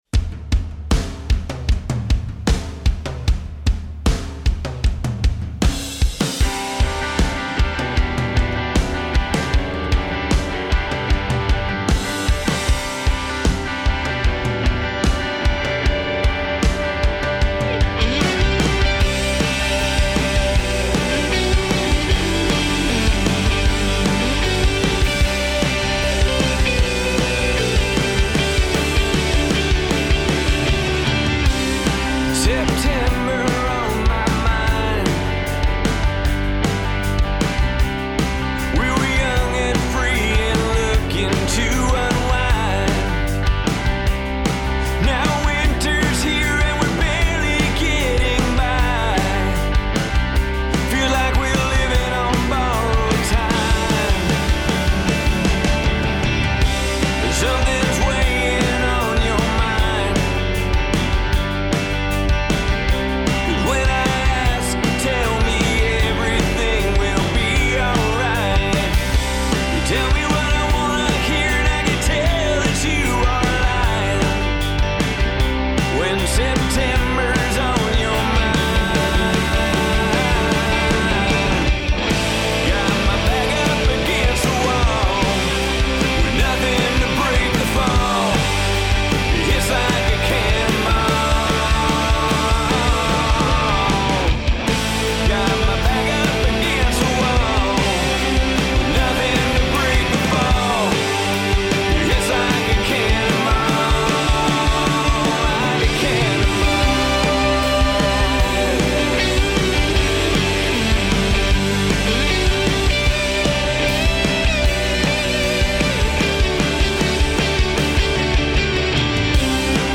play a couple new songs live